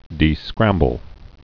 (dē-skrămbəl)